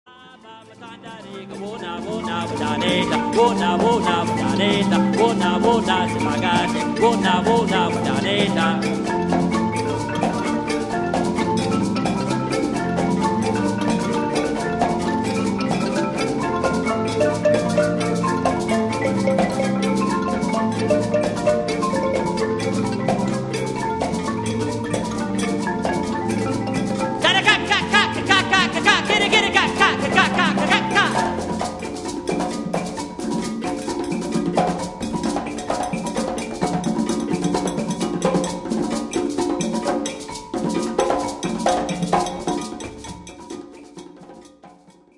for Marimba Ensemble